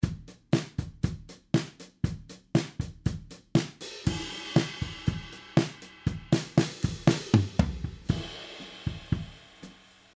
Overhead Mics:
Overheads